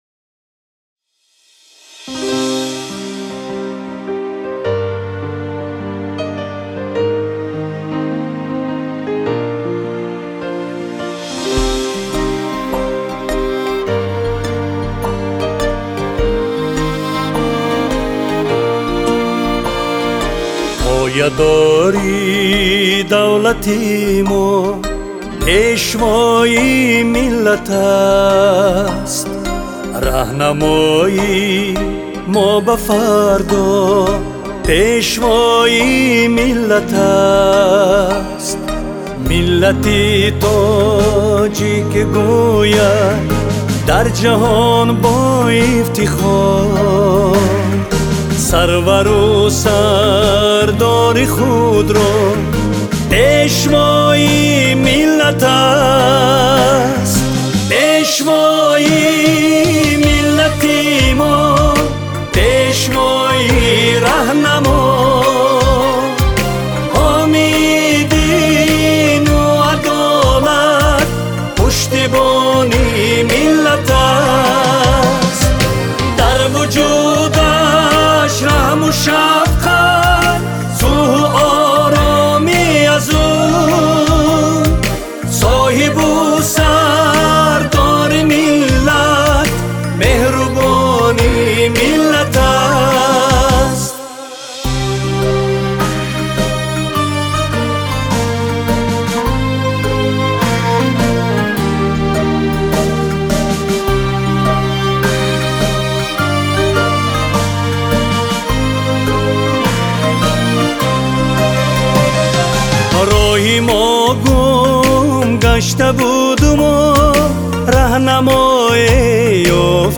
Сабти овози ғазалҳо